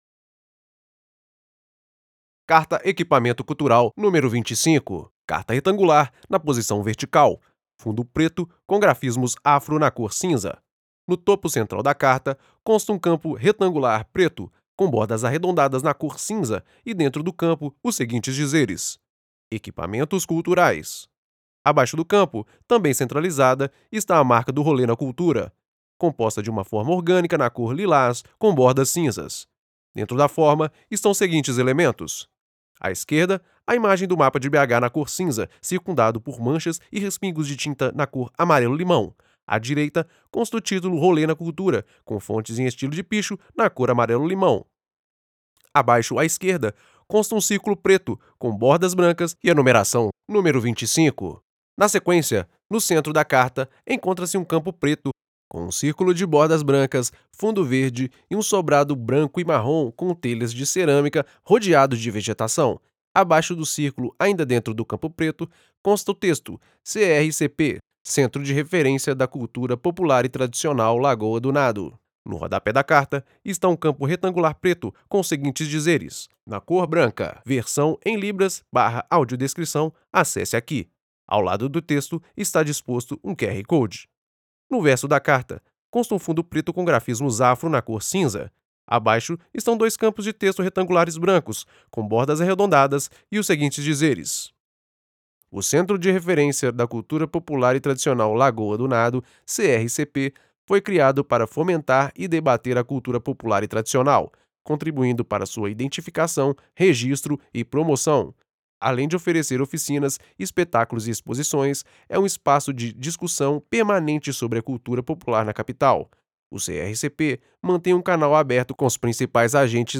Audiodescrição CRCP
audiodescricoes-EQUIPAMENTOS-25.mp3